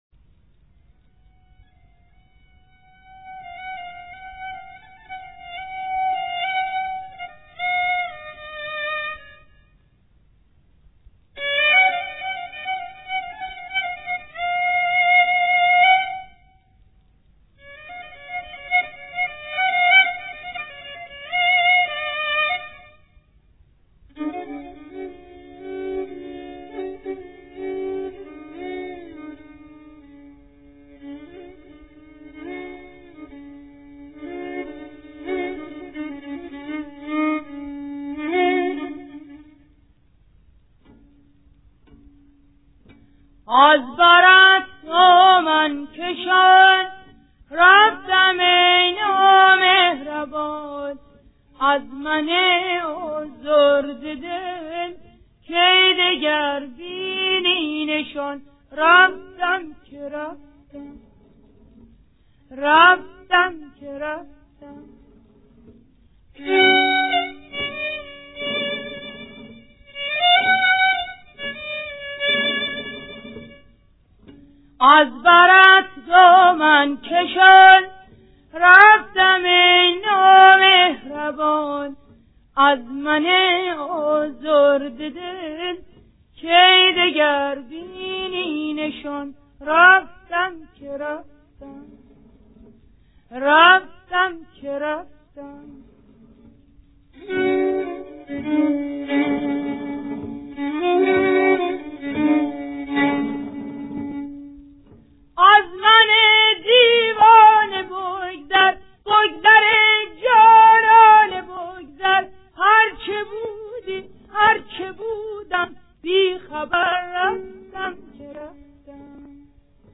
مایه: شوشتری